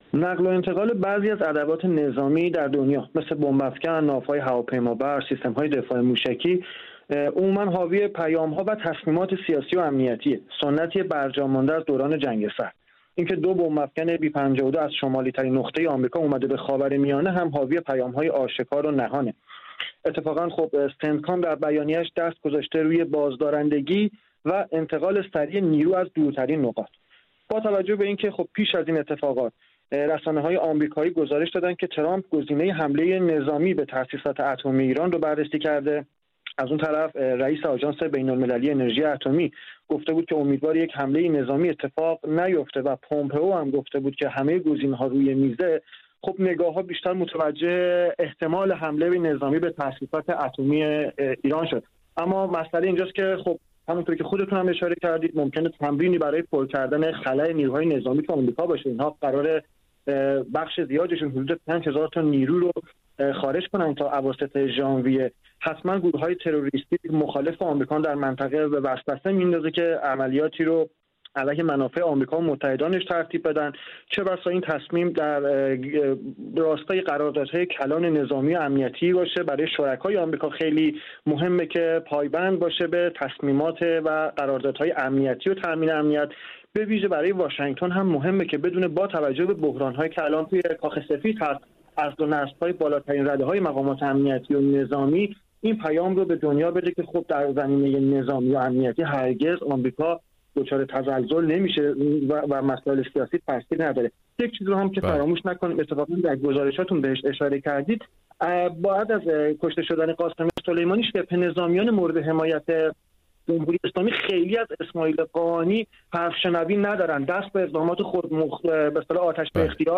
گفت و گویی